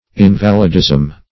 Search Result for " invalidism" : Wordnet 3.0 NOUN (1) 1. chronic ill health ; The Collaborative International Dictionary of English v.0.48: Invalidism \In"va*lid*ism\, n. The condition of an invalid; sickness; infirmity.